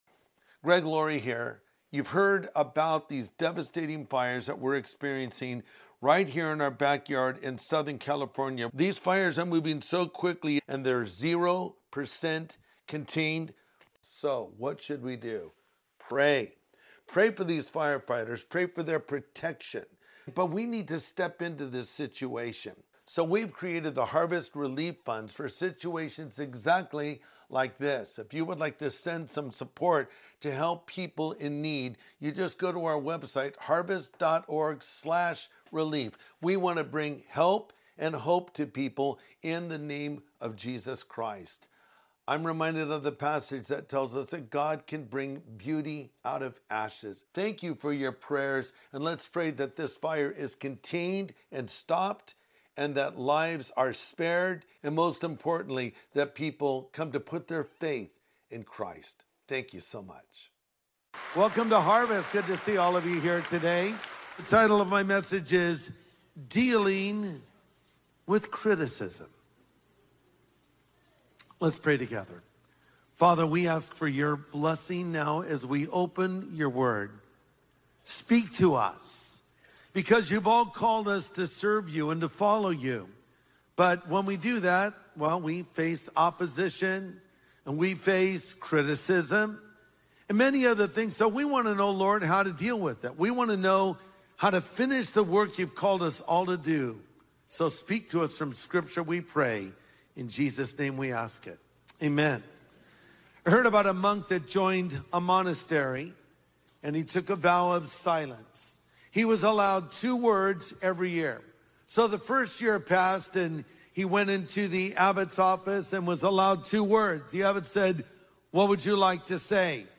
Dealing with Criticism | Sunday Message